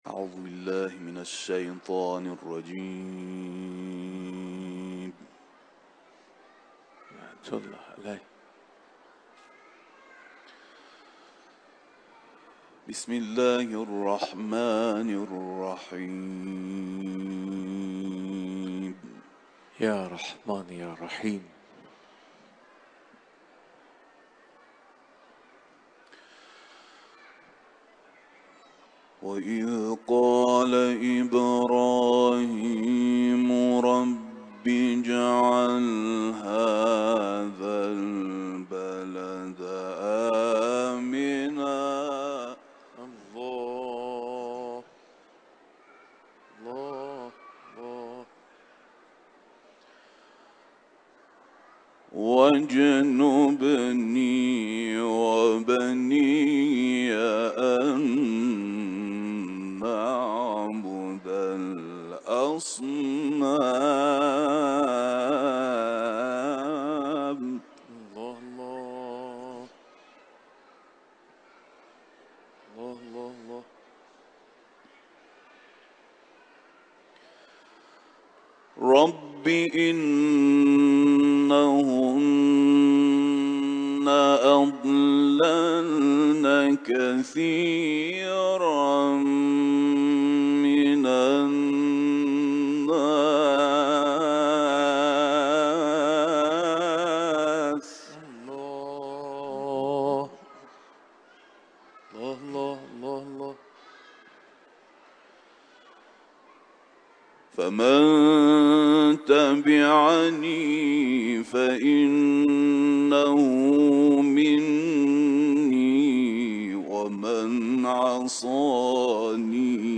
Kur’an-ı Kerim’den ayetler tilavet etti